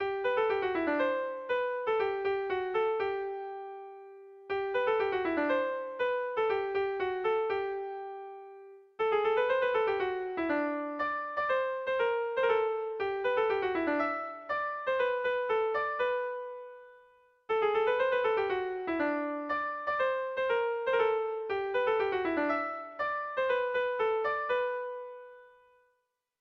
Irrizkoa
Zortzikoa, txikiaren moldekoa, 4 puntuz (hg) / Lau puntukoa, txikiaren modekoa (ip)
AABA2